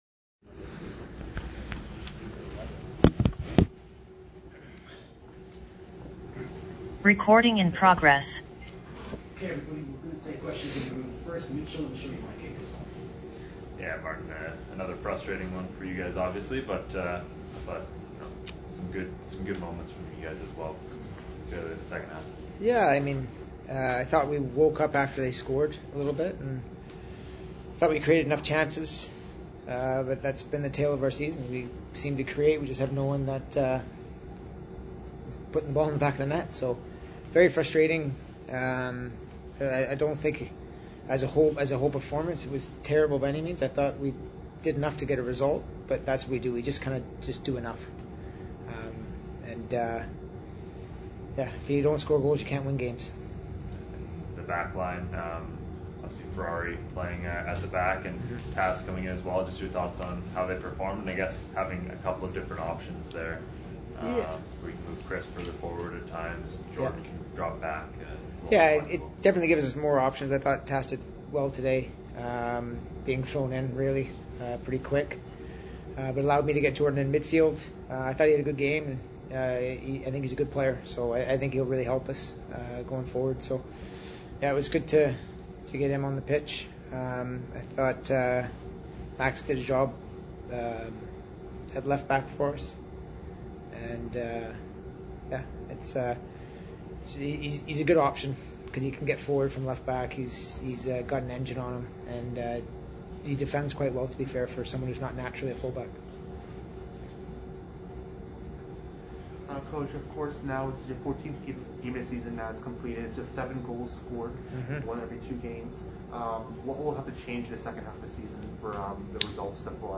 July 08, 2022...York United FC vs Forge FC post game press conferences
post game questions and answers for Forge FC